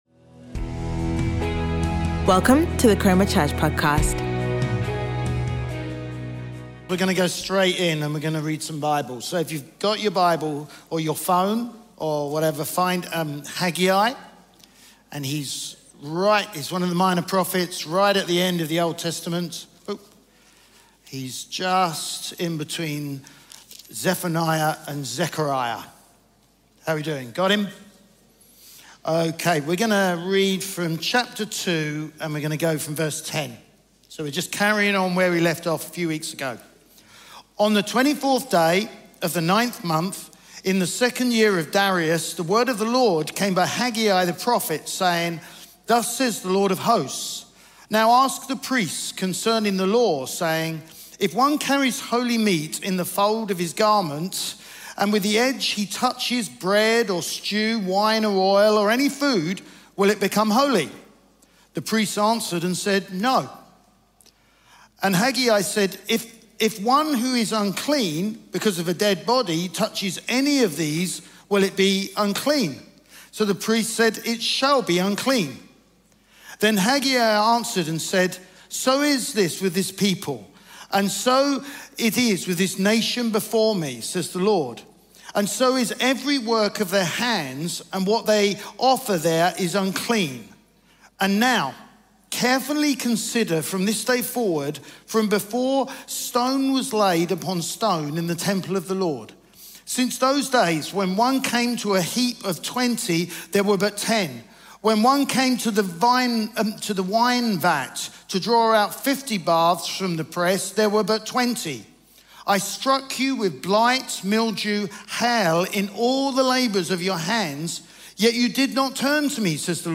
Chroma Church Live Stream
Chroma Church - Sunday Sermon